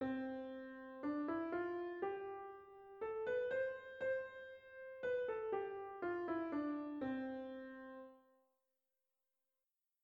A continuación tienes un ejemplo de este grupo de figuras dentro de una melodía sencilla:
Ejemplo de dos semicorcheas y corchea en una melodía